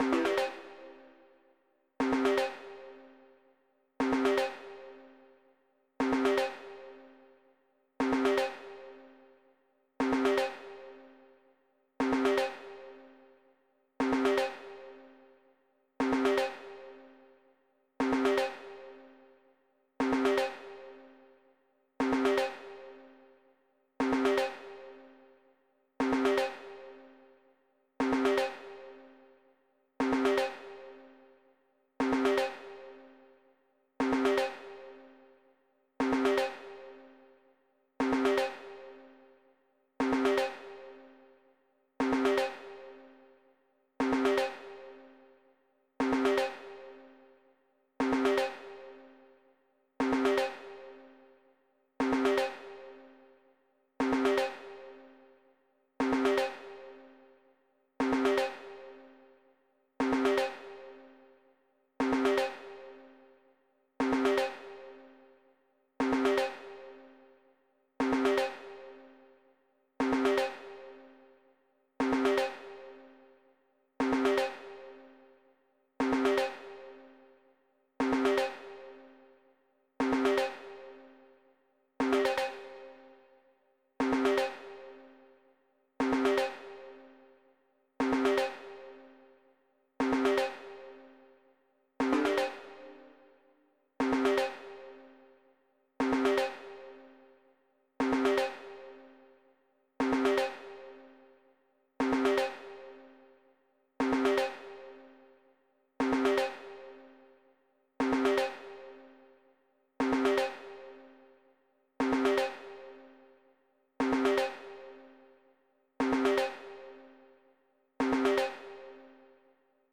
Ambient Calm 05:00